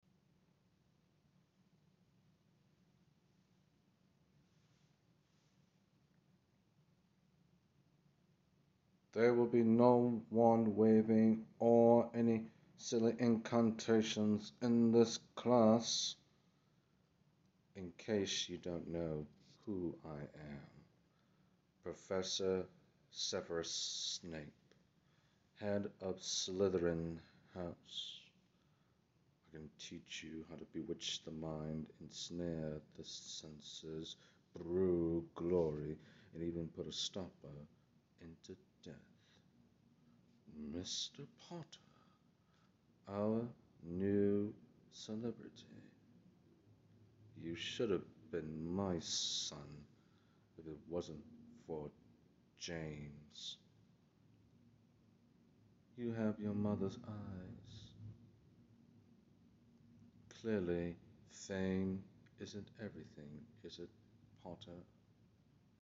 Voice impression of Severus Snape sound effects free download